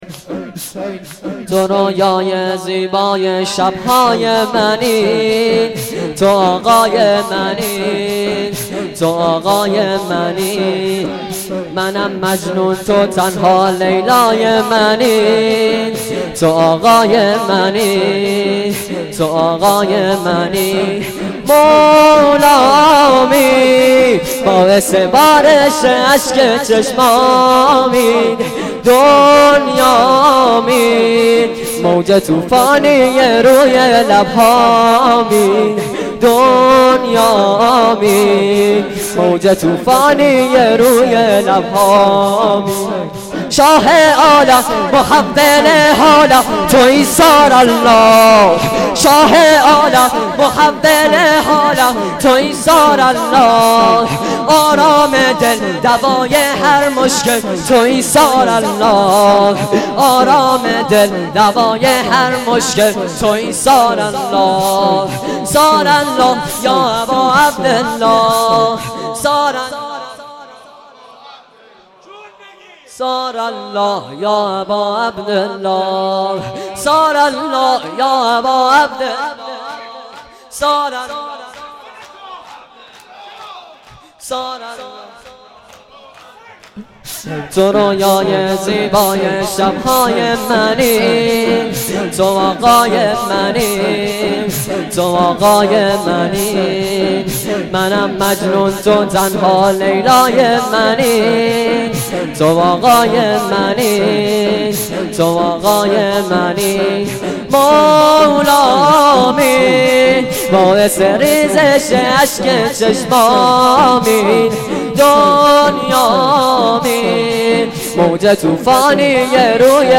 شور شب سوم فاطمیه
مداحی
شور.mp3